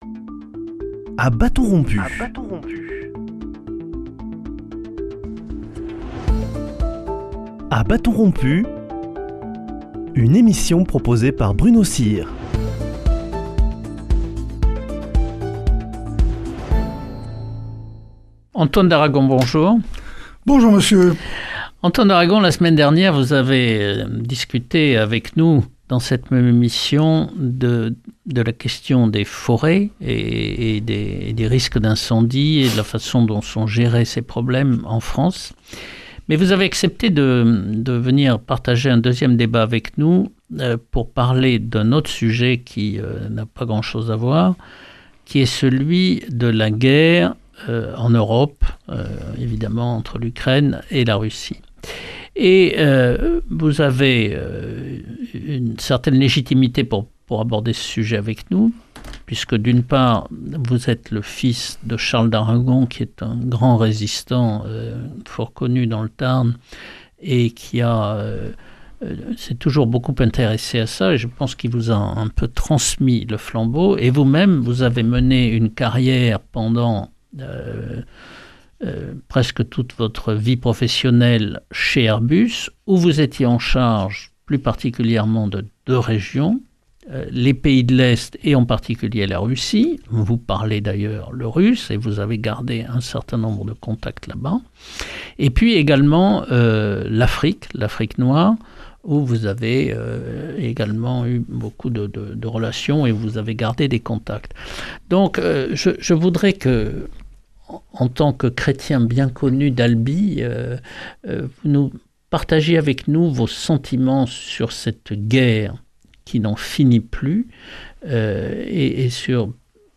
Dans un second entretien il aborde, à la lumière des réseaux qu’il a gardé dans les pays de l’Est et dans les pays d’Afrique Subsaharienne, de l’évolution du conflit qui oppose la Russie à l’Ukraine et de son extension diplomatique en Afrique.